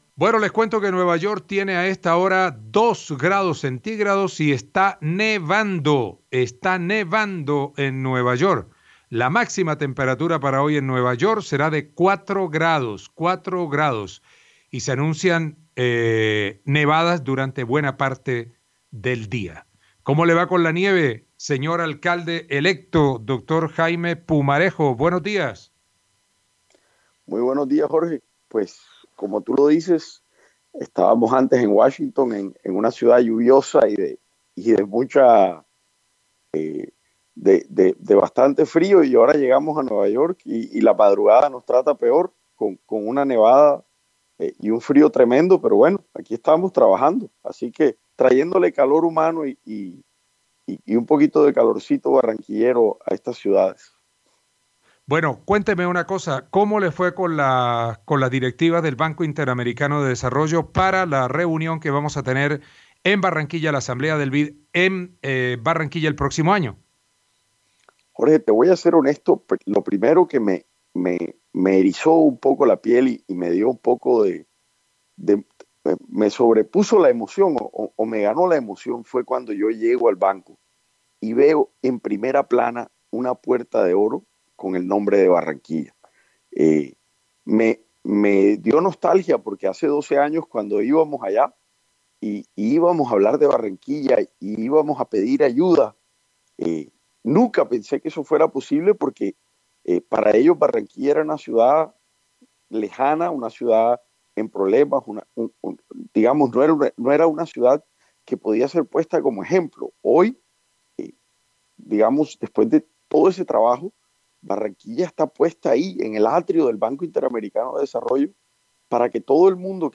El alcalde electo de Barranquilla, Jaime Pumarejo, dijo desde Nueva York que el Banco Interamericano de Desarrollo tomó a Barranquilla como ejemplo de gran ciudad.